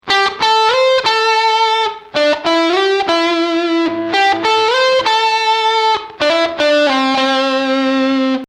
A versatile distortion that will cut right through the mix.
Clips 2 and 3: Max. Gain
guitar - effect - cabinet simulator - sound card (software reverb)